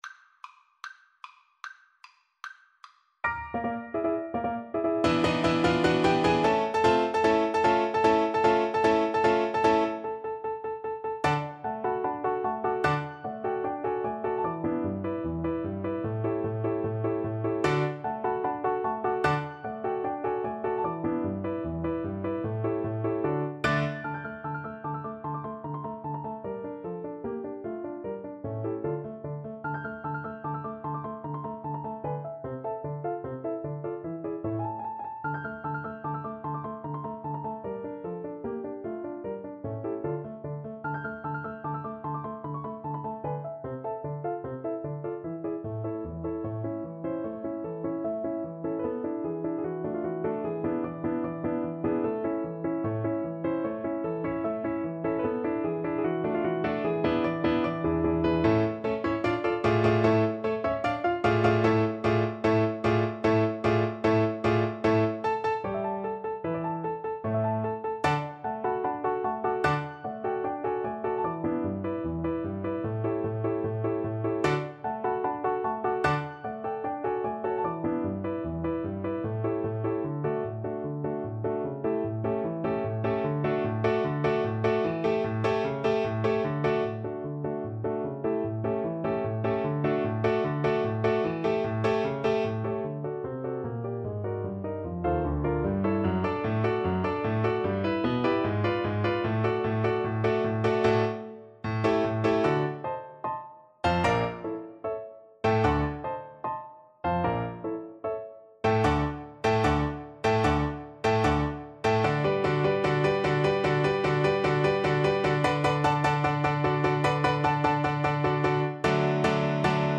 Allegro vivacissimo ~ = 150 (View more music marked Allegro)
Classical (View more Classical Viola Music)